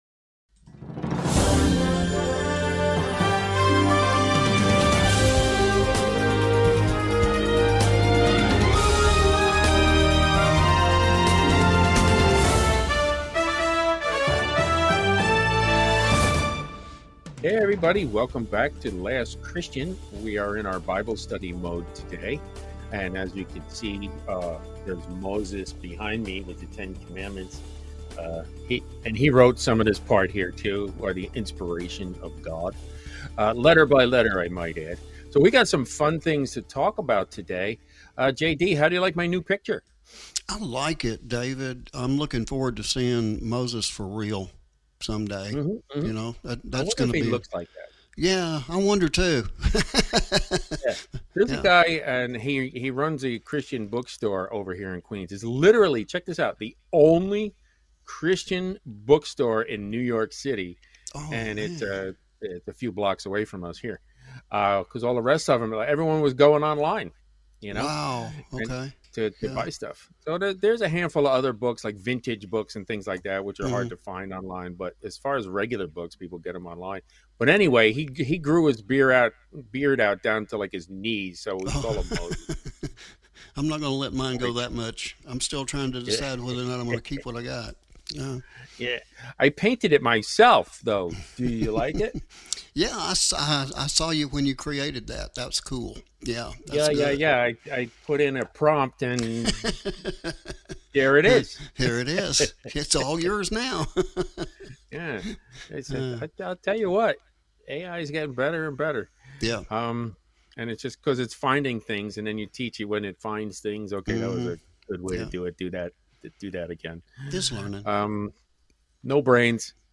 Weekly Bible Study